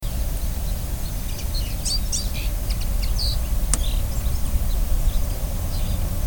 Lebensraum Wiese im Natura 2000-Gebiet Lendspitz-Maiernigg
Audioaufnahmen aus dem Schutzgebiet
Bluthänfling
haenfling.mp3